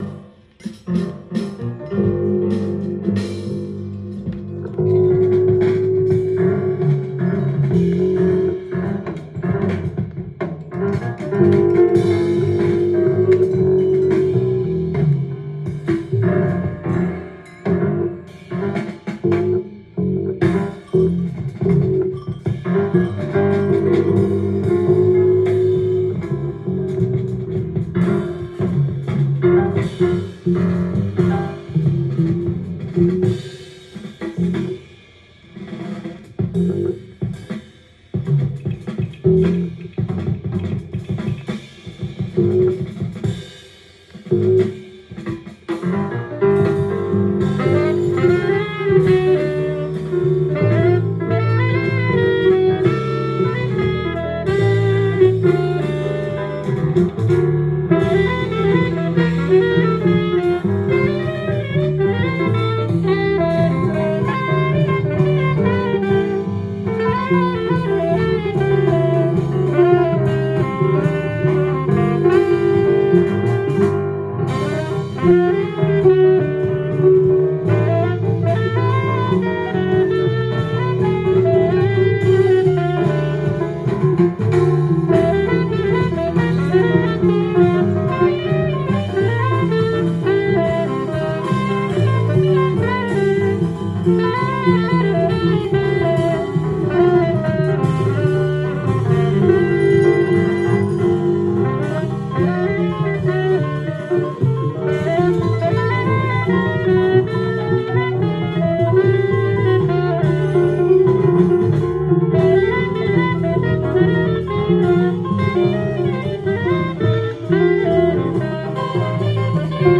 ジャンル：JAZZ-ALL
店頭で録音した音源の為、多少の外部音や音質の悪さはございますが、サンプルとしてご視聴ください。